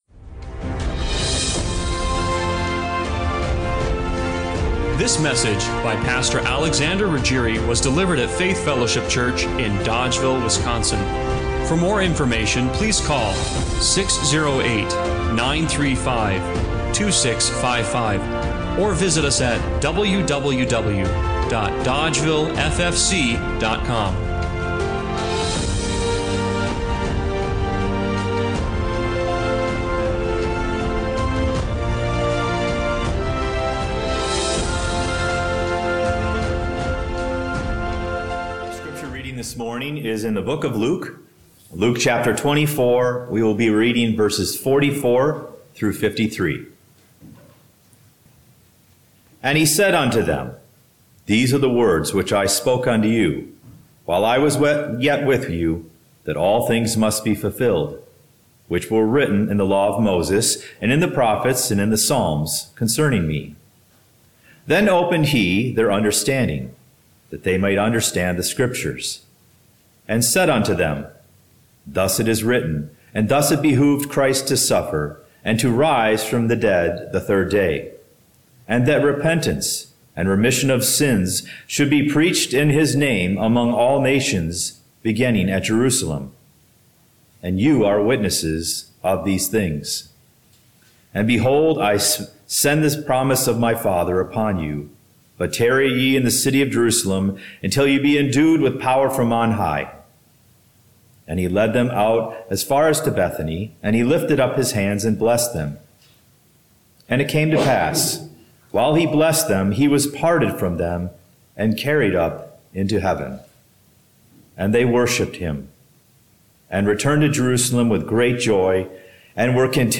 Luke 24:44–53 Service Type: Sunday Morning Worship What kind of love crosses galaxies to find you?